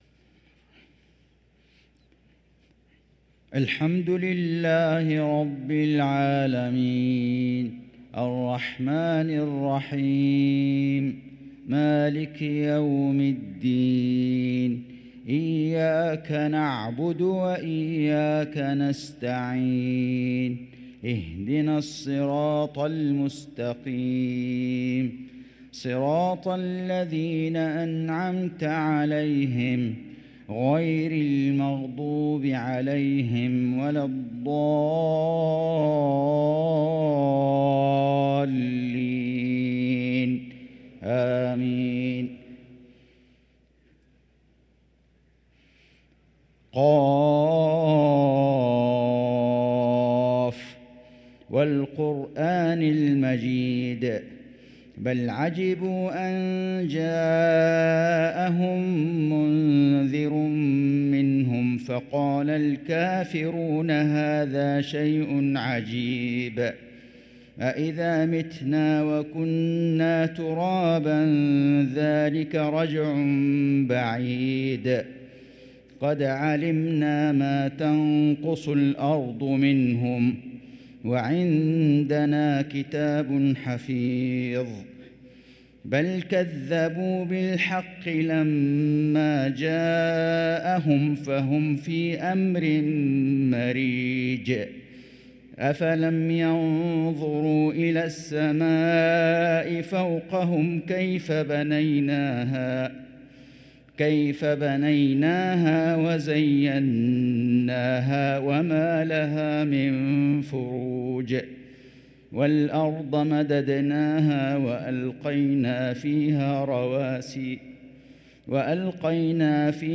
صلاة المغرب للقارئ فيصل غزاوي 12 صفر 1445 هـ